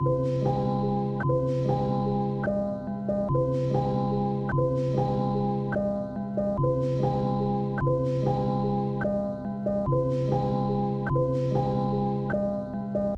逻辑钢琴
Tag: 73 bpm Hip Hop Loops Piano Loops 2.22 MB wav Key : Unknown